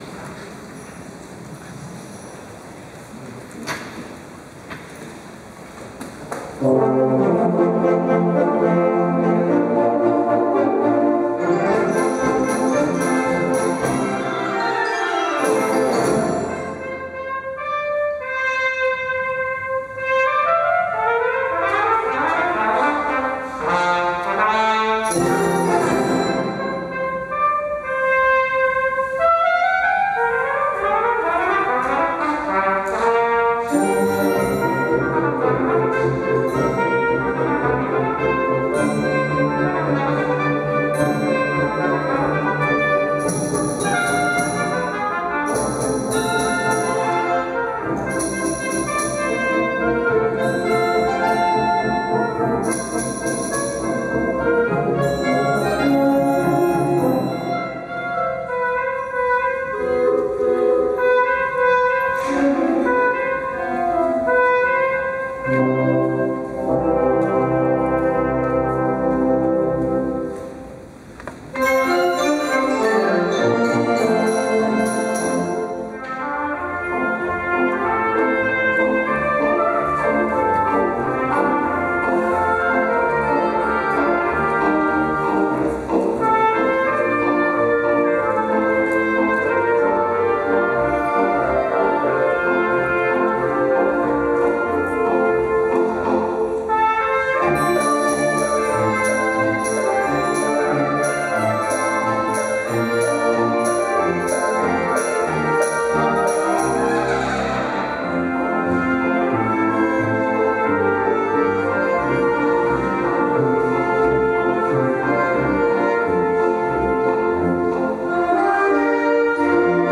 Voicing: Trumpet Solo